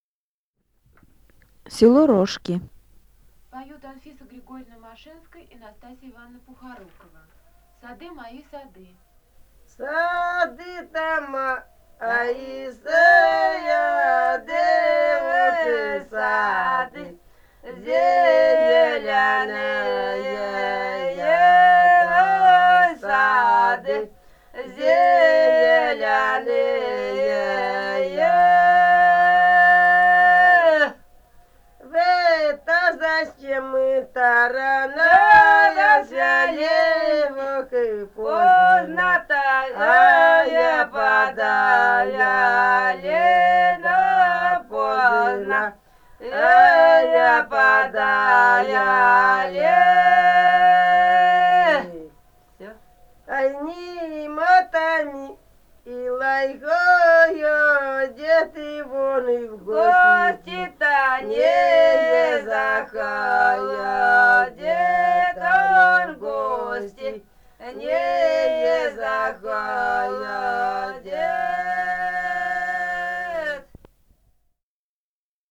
полевые материалы
«Сады-то мои, сады» (лирическая).
Алтайский край, с. Маральи Рожки Чарышского района, 1967 г. И1002-04